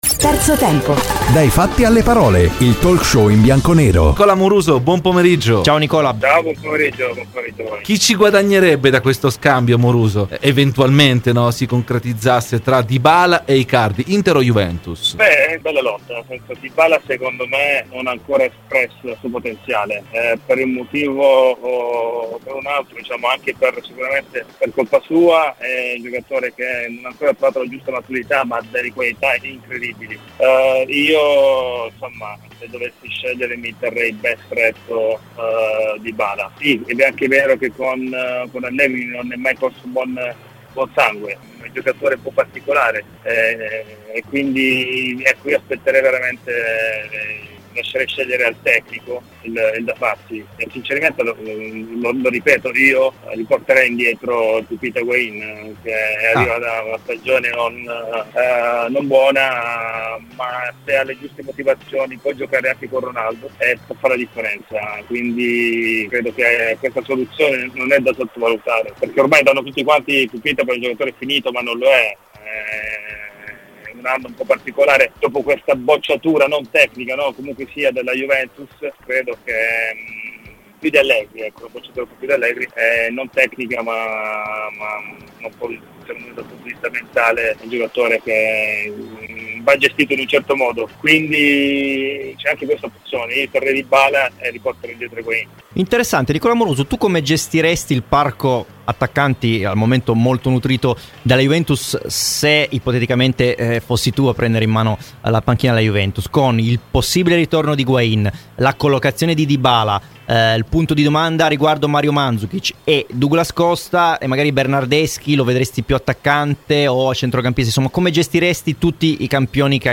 Nicola Amoruso, ai microfoni di "Terzo tempo" su Radio Bianconera. © registrazione di Radio Bianconera